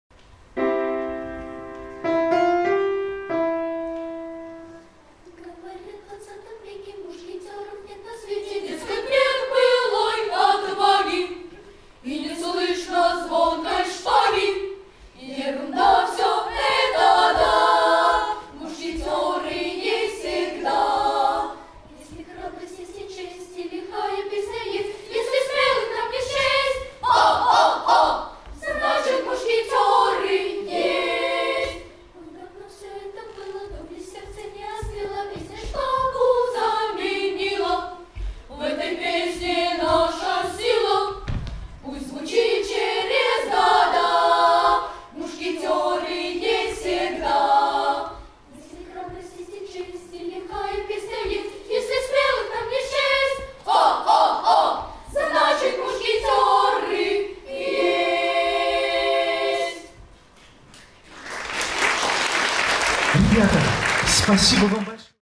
В исполнении концертной группы хора мальчиков